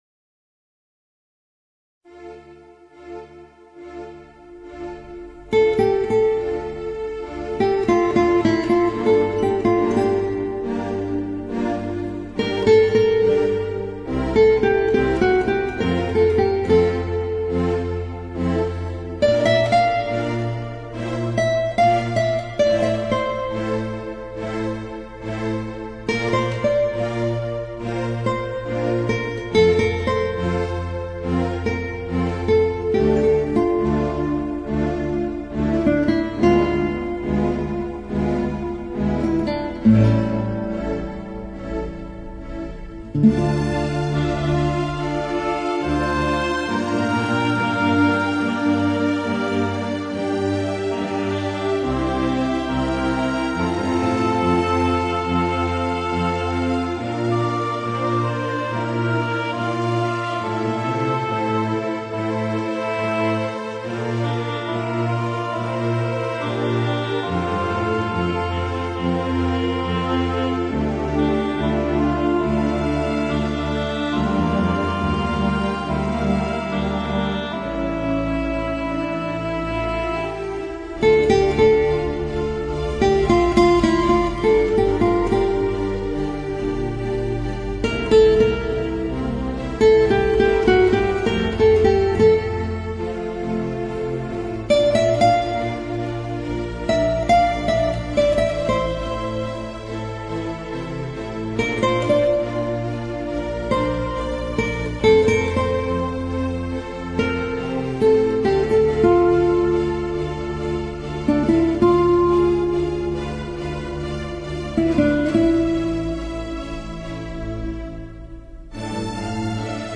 精致的古典风有了流浪者的潇洒
让吉他轻轻飞起